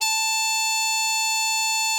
bari_sax_081.wav